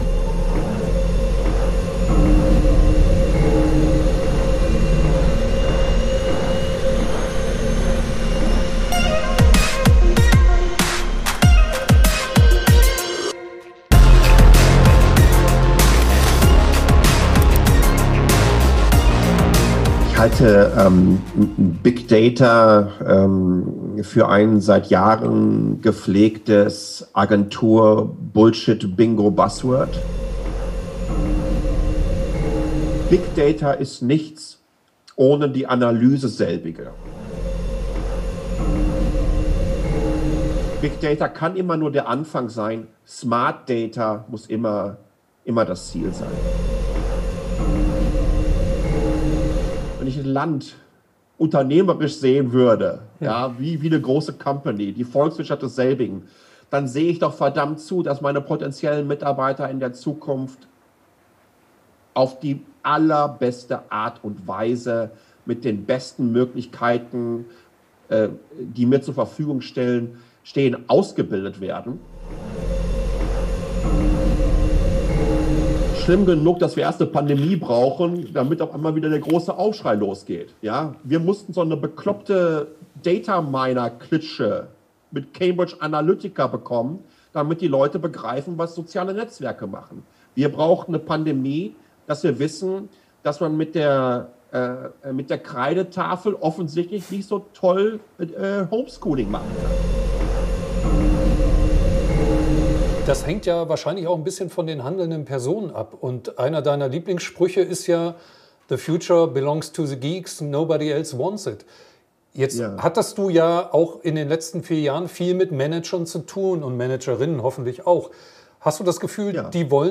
Es geht um unumgängliche Realitätsschocks, die Notwendigkeit von Redundanzen und nicht allzu smarte Daten. Das Interview wurde im Frühjahr 2021 geführt.